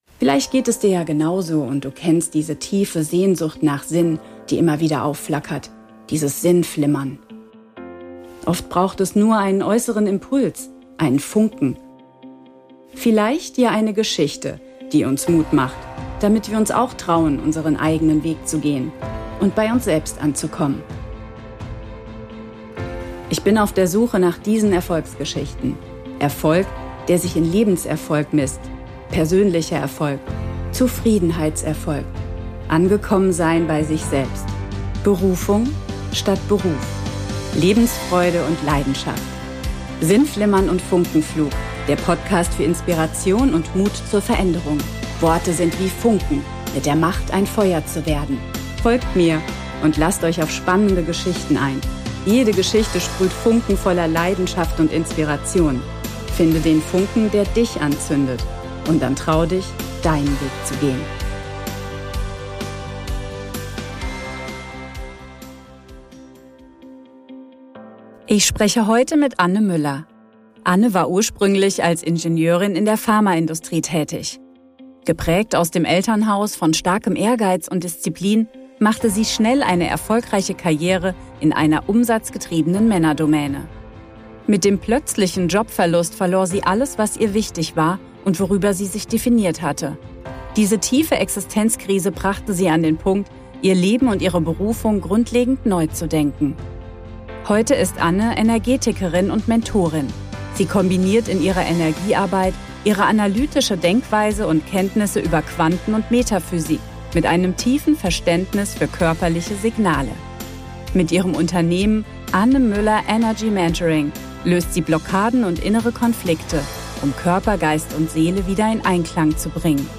#015 Interview